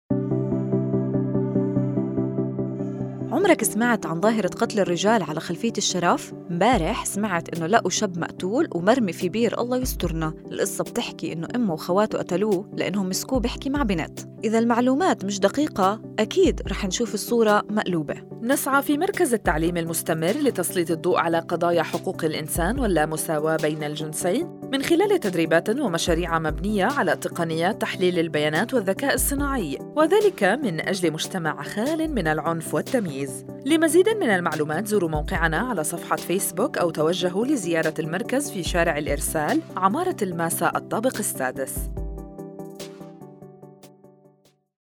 Radio Spot 2